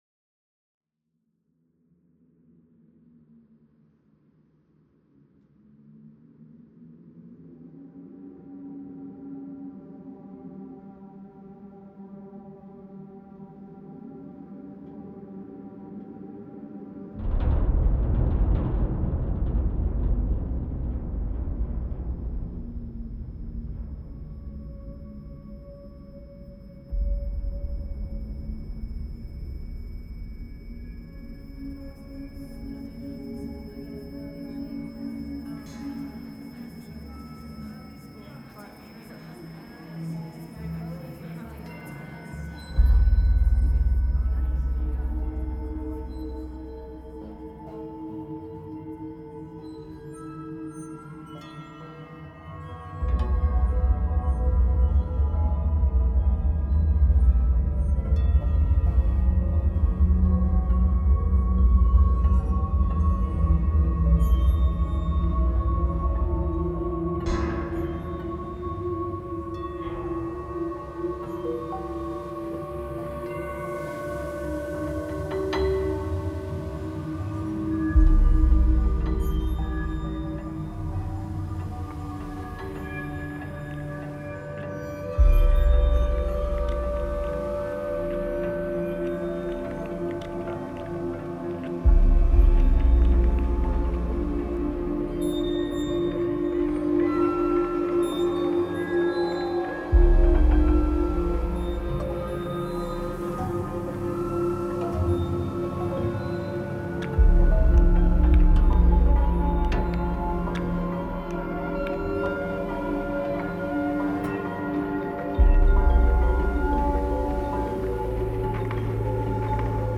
Composition de musique concrète en Non-Dits pour : Bruits de freins usés - Volet roulant peu lubrifié - Tasses en porcelaine de l’époque Ming...